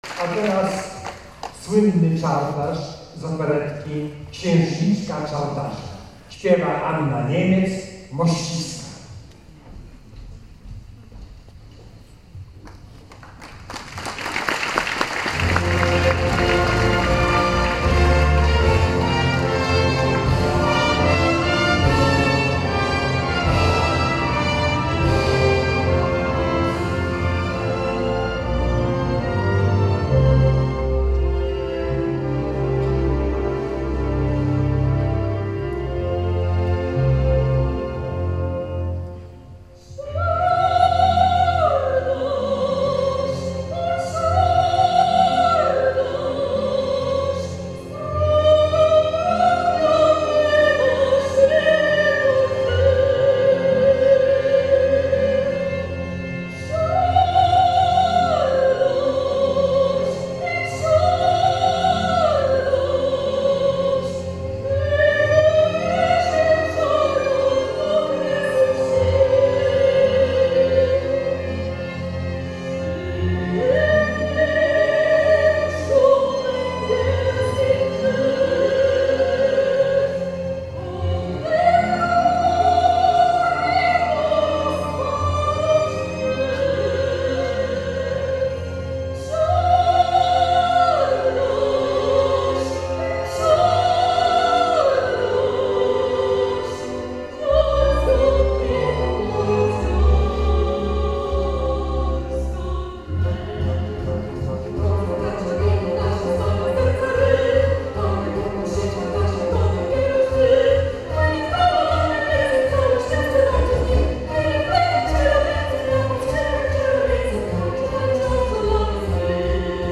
Posłuchaj mnie - utwory wykonane z towarzyszeniem orkiestry